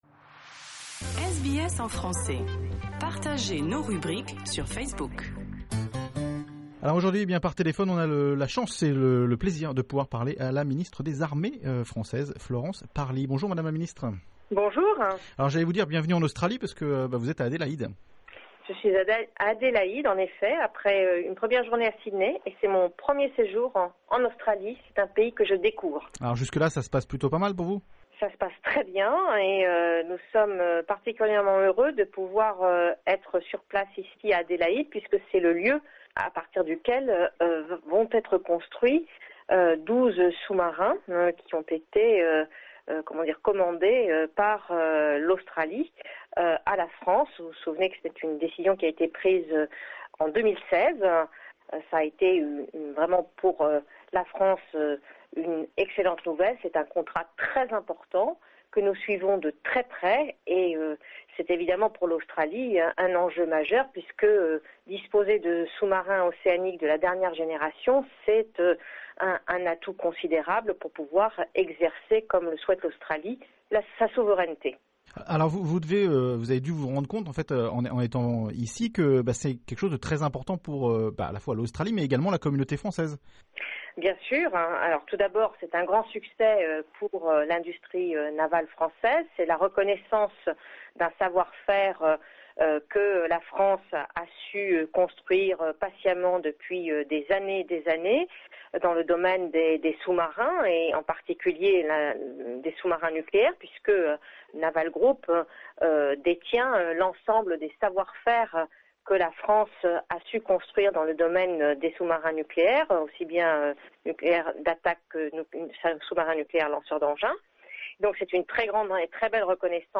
Entretien exclusif avec Mme la ministre des Armées française Florence Parly, lors de sa visite a Adelaide.